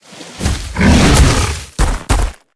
Sfx_creature_snowstalker_attack_bear_01.ogg